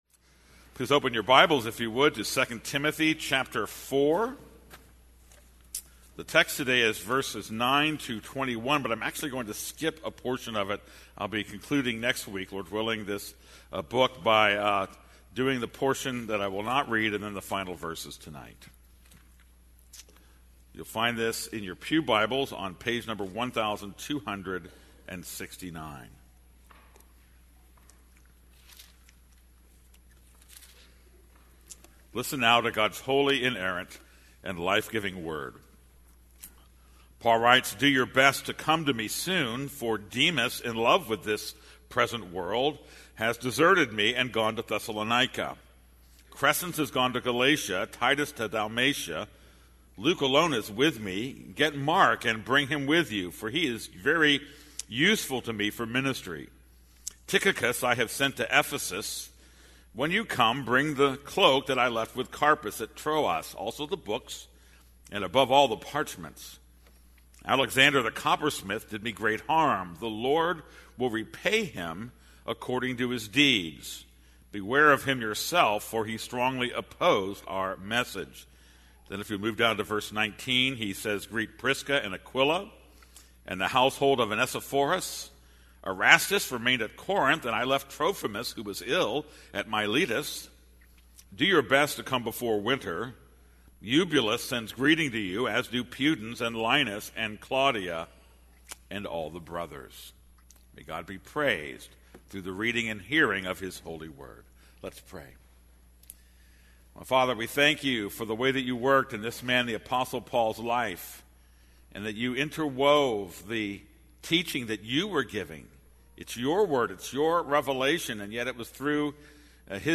This is a sermon on 2 Timothy 4:9-15; 19-21.